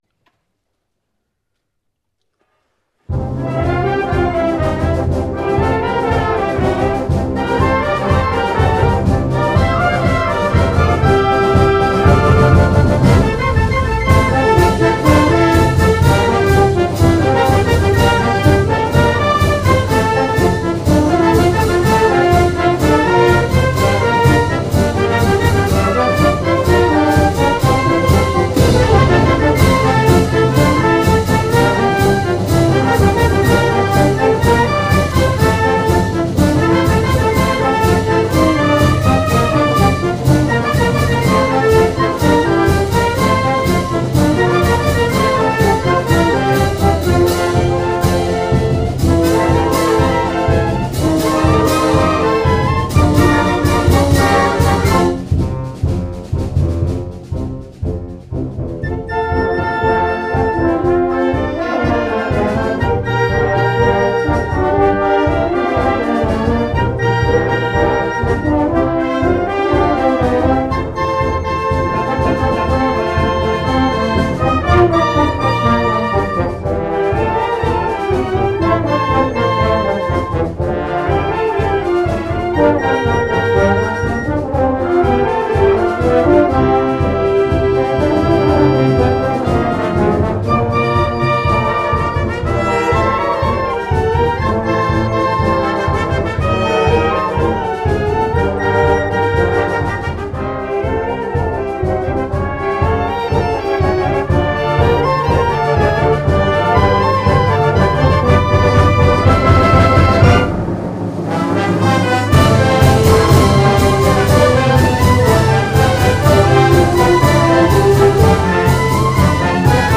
Nuevo pasodoble para la comparsa de Labradores
Este pasodoble refleja tu alegría de vivir, y tantas cosas buenas que nos has dado ahora que cumples tus 80 años«.
grabó la pieza en uno de sus ensayos.
La pieza se estrena oficialmente en el Desfile del Pasodoble.